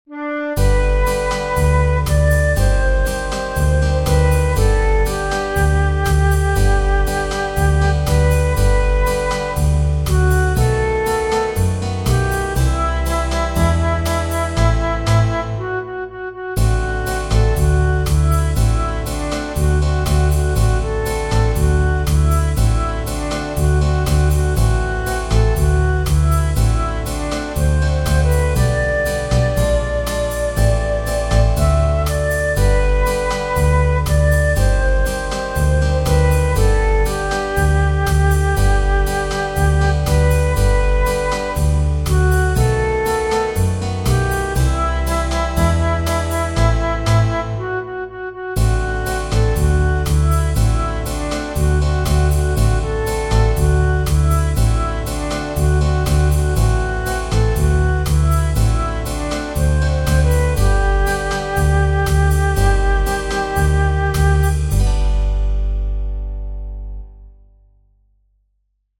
Genere: Moderne